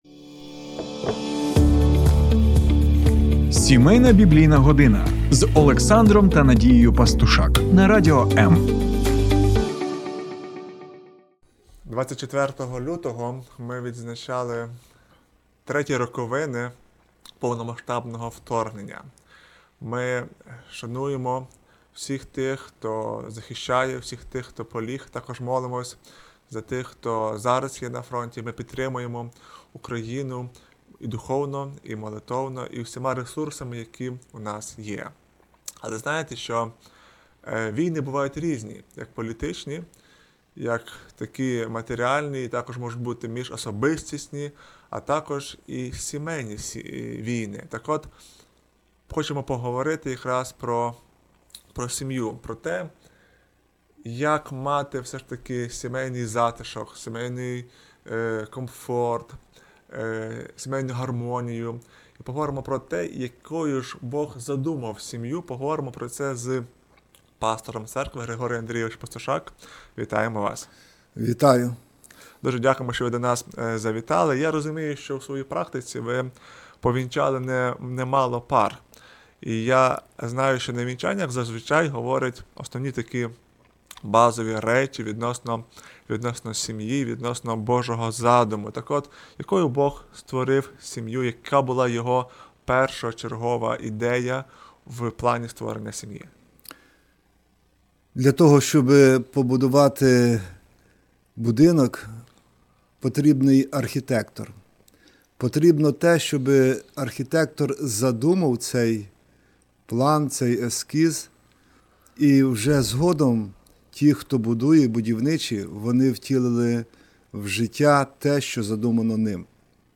Божий задум сім'ї 25/02/2025 Розуміння сім'ї у кожного може бути своє, але який же ж був Божий задум при створенні сім'ї і як тепер нам збудувати щасливу і міцну сім'ю? Про це поговоримо з пастором церкви